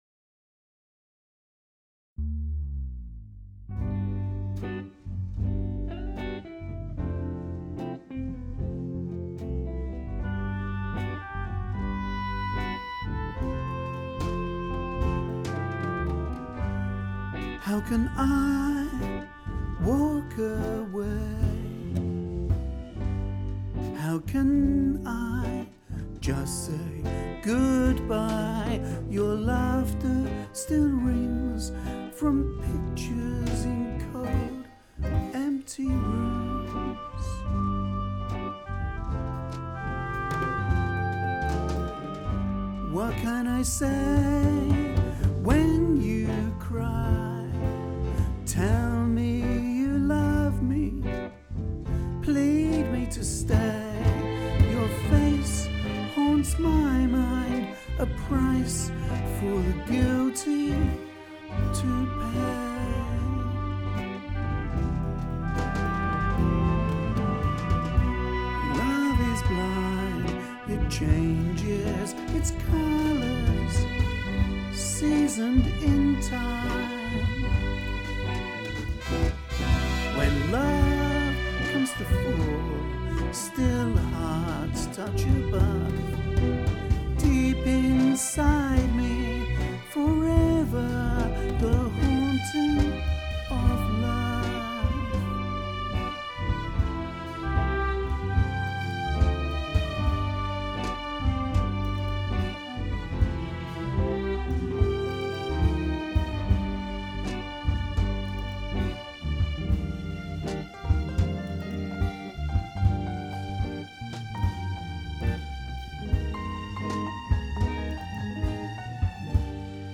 The Haunting - vocal style search #05 - 13th November 2017